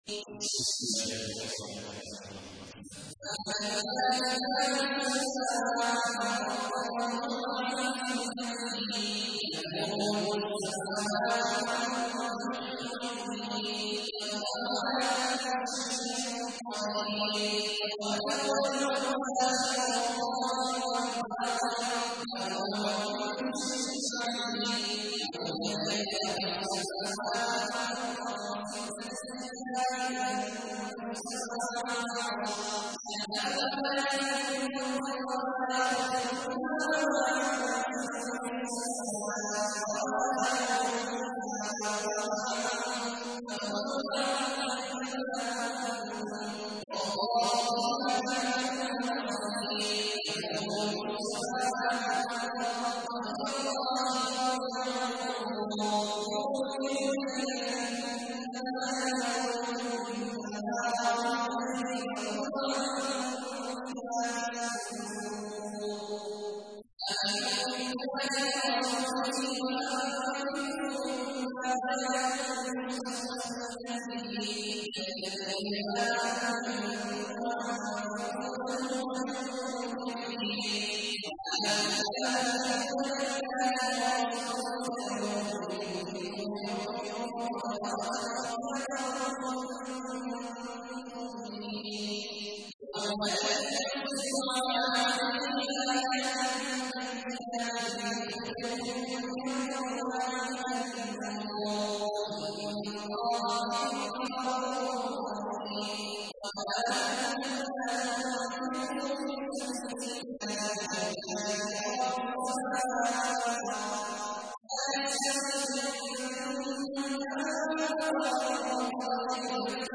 تحميل : 57. سورة الحديد / القارئ عبد الله عواد الجهني / القرآن الكريم / موقع يا حسين